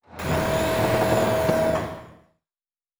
pgs/Assets/Audio/Sci-Fi Sounds/Mechanical/Servo Big 1_2.wav at master
Servo Big 1_2.wav